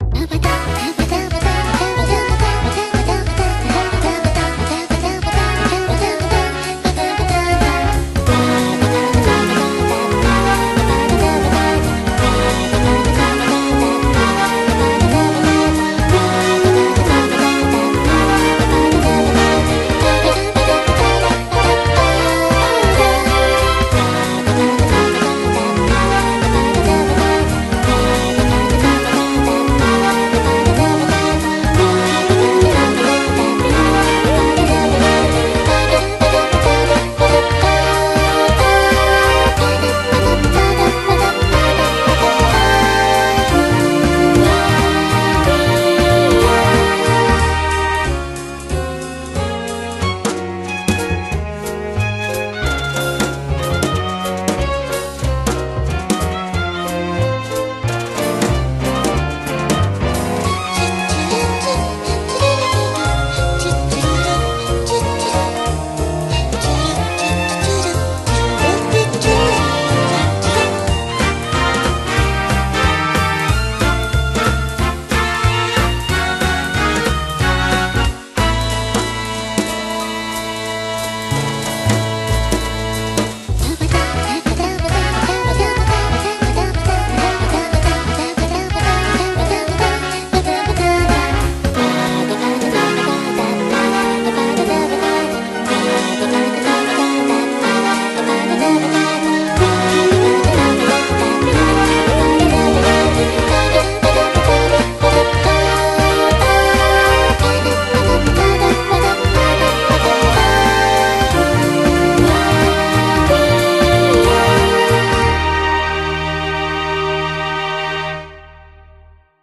BPM184
Audio QualityMusic Cut